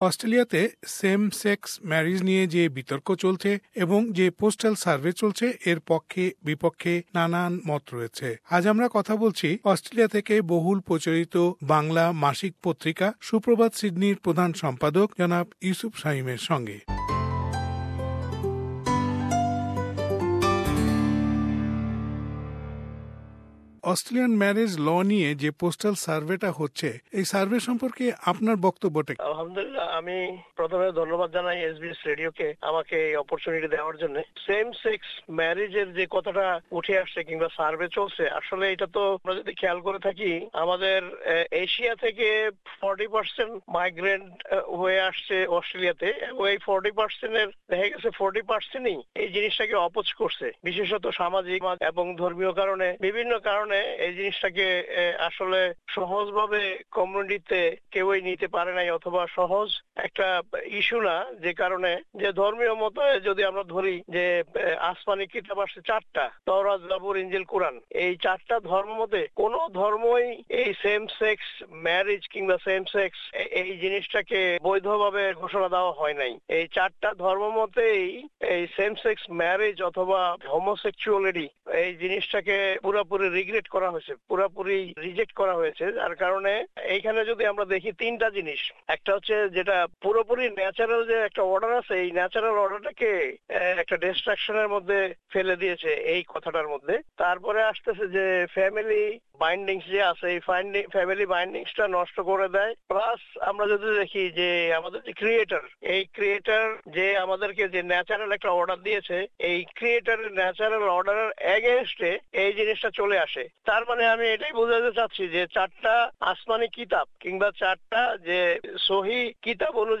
Same-Sex Marriage Postal Survey : Interview